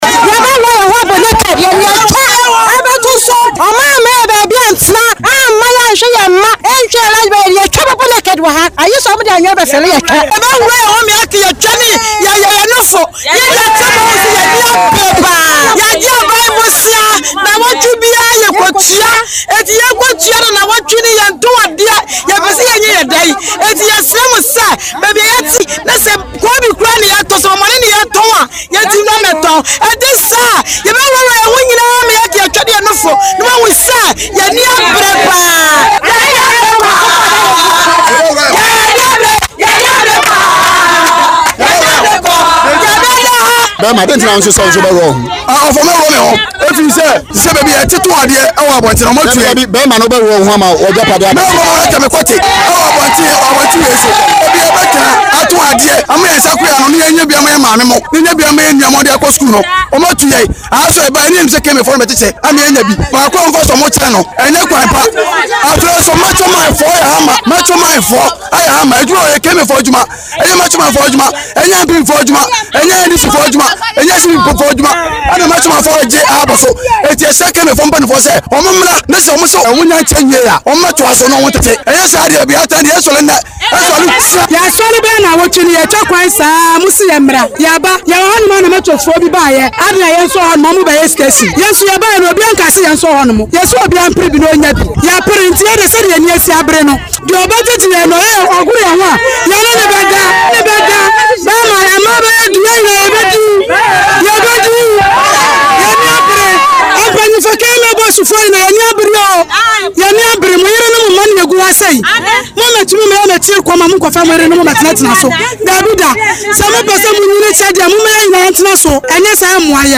“We will go naked on the streets if the city authorities refuse to allocate us place to sell our wares. We have contracted loans from the banks to do businesses, if we do not get place to sell, how we can repay the loans,” she angrily said.